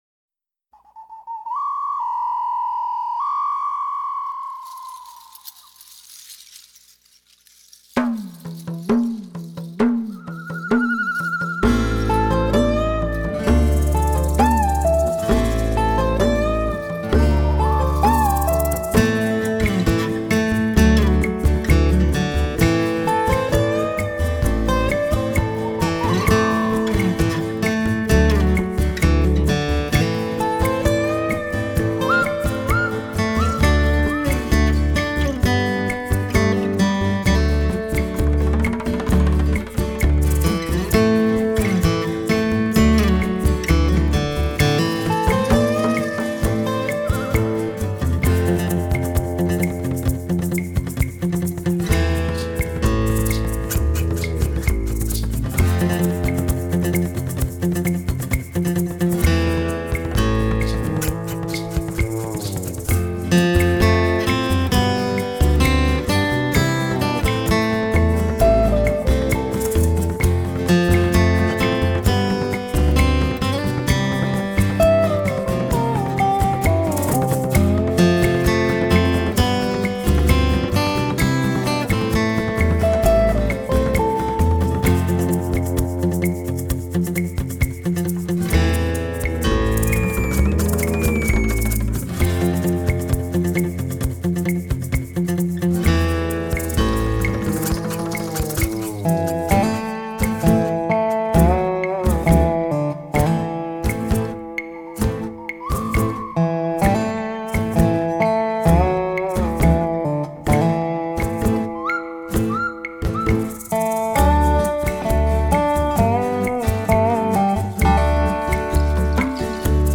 风格流派: Pop
整体上的音乐风格偏向于乡村音乐